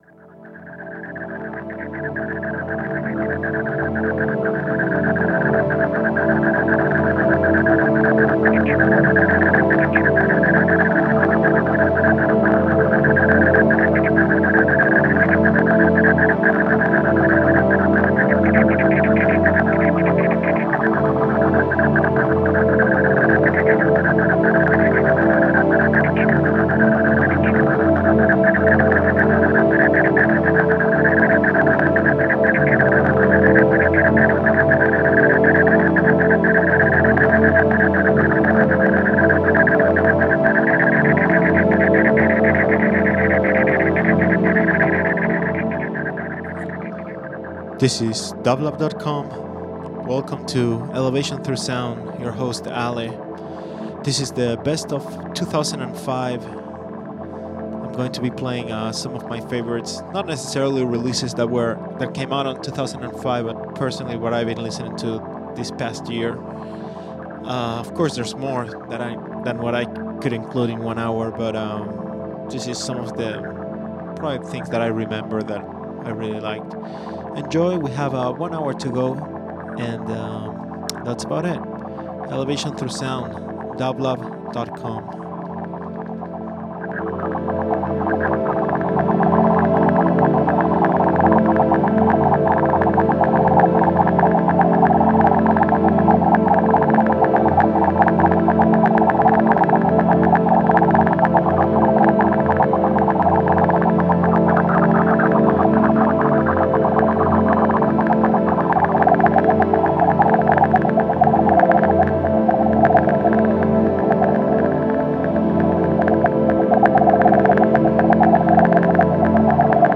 Ambient Electronic Folk Rock